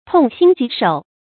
注音：ㄊㄨㄙˋ ㄒㄧㄣ ㄐㄧˊ ㄕㄡˇ
痛心疾首的讀法